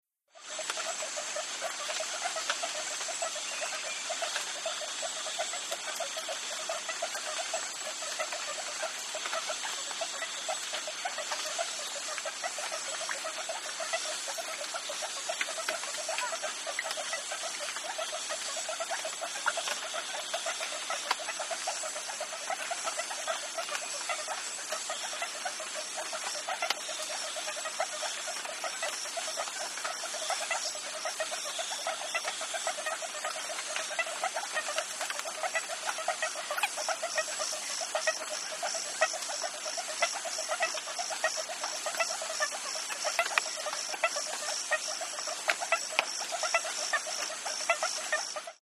ANIMALS-JUNGLE BGS RAIN FOREST: Close up small frog, water dripping onto leaves, single cricket in background, La Selva, Costa Rica.